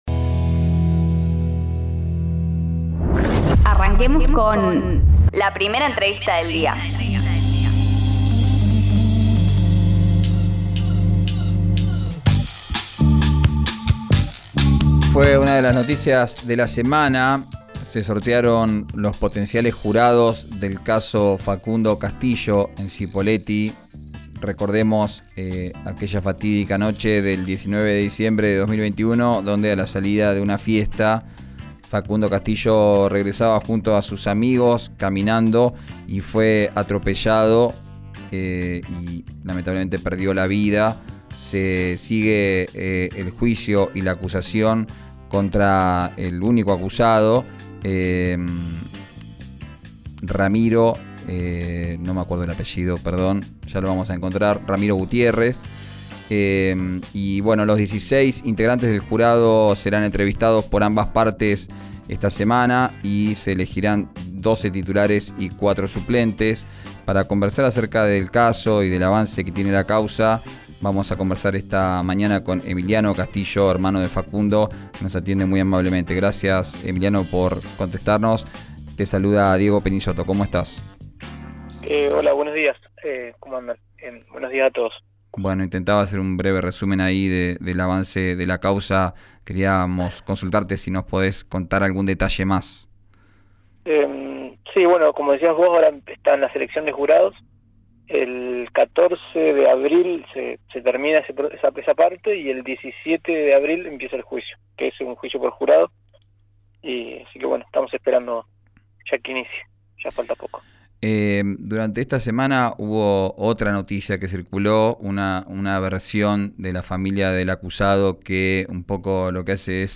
No me molesta que ellos hablen, porque nosotros tenemos un trabajo muy sólido, por eso estamos esperando el juicio”; dijo en declaraciones a «Arranquemos», por RÍO NEGRO RADIO.
en «Arranquemos», por RÍO NEGRO RADIO